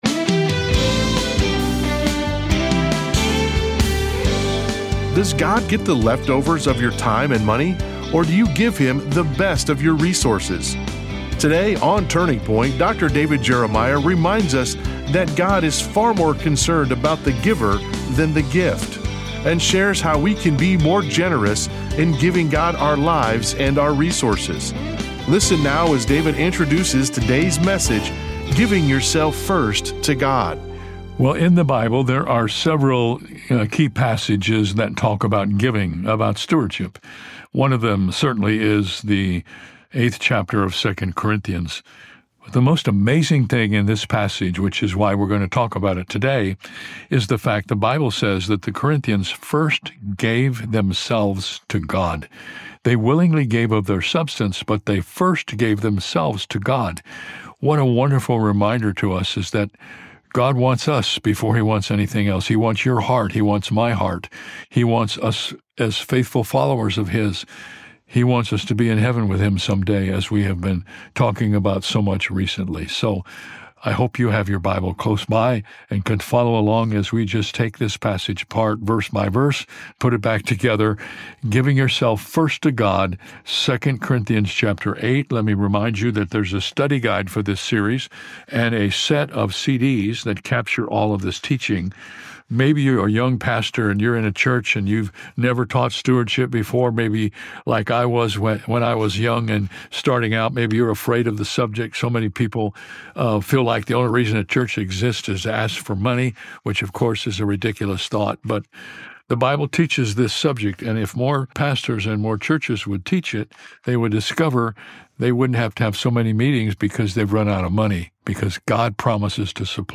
David Jeremiah reminds us that God is far more interested in the giver than He is in the gift. In this message, David teaches the key to stewardship.